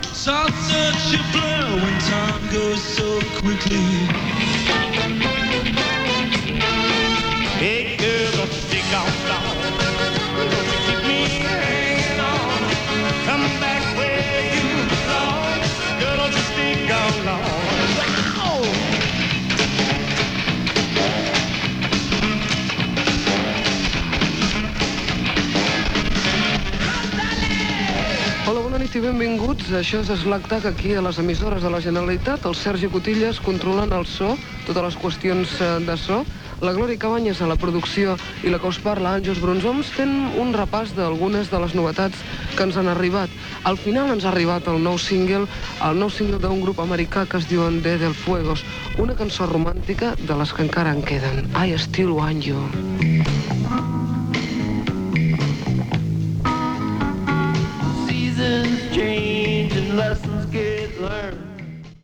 Sintonia, presentació, equip, tema musical
FM